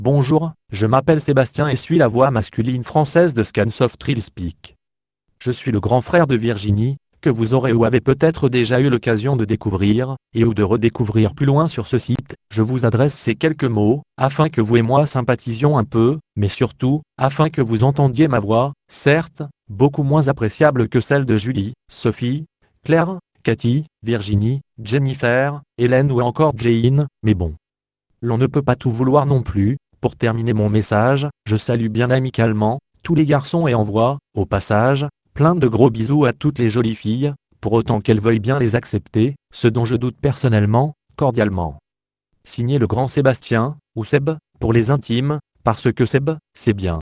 Texte De Démonstration Lu Par Sébastien (Nuance RealSpeak; distribué sur le site de Nextup Technology; homme; français)